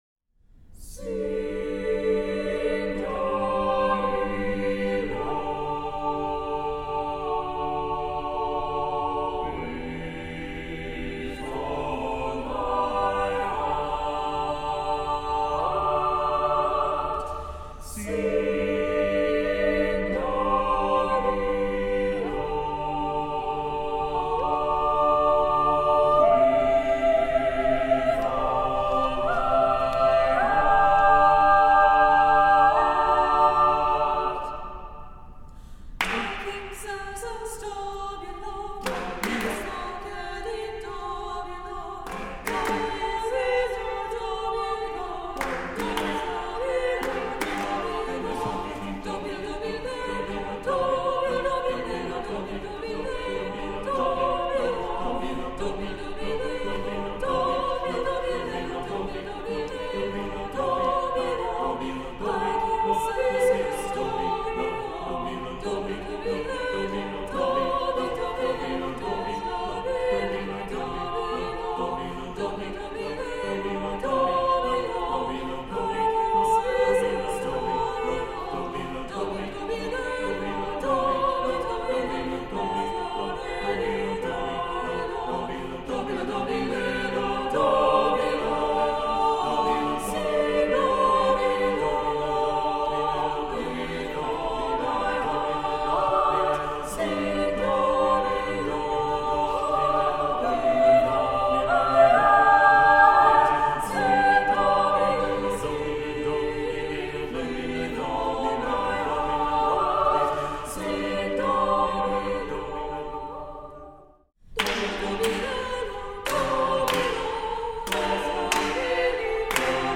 Voicing: SSAATBB